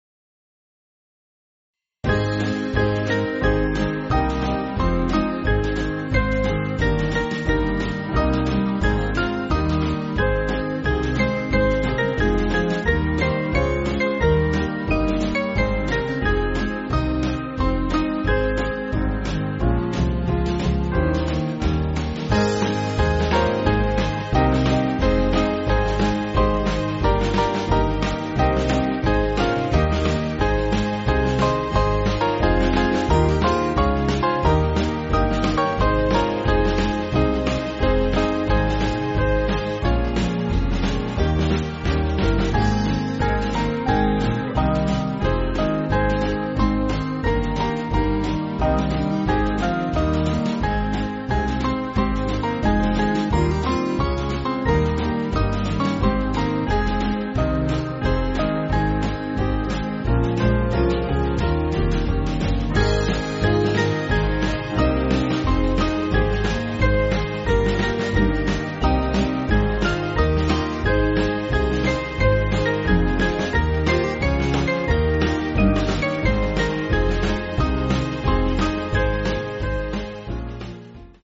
Small Band
(CM)   6/Ab